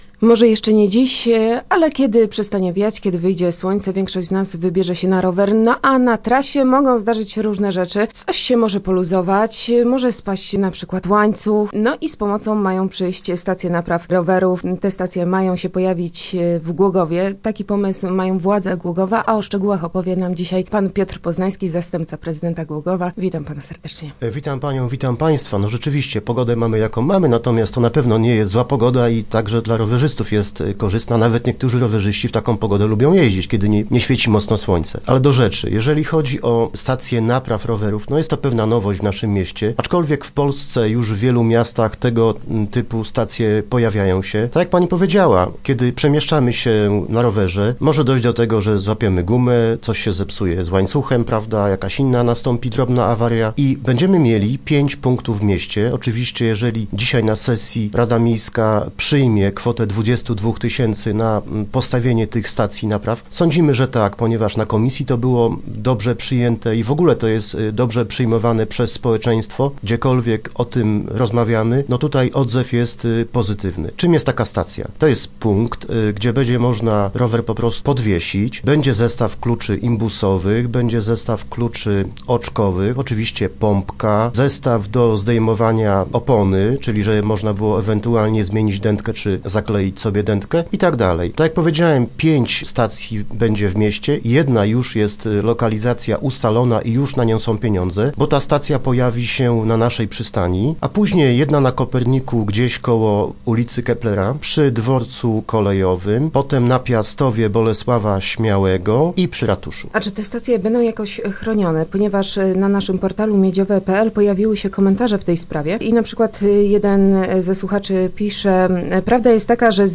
W Głogowie mają powstać stacje napraw rowerów, ale to nie wszystko. Władze miasta chcą postawić koło przystani Marina także wypożyczalnię jednośladów. Z Piotrem Poznańskim, zastępcą prezydenta Głogowa, rozmawialiśmy również o rozbudowie ścieżek rowerowych.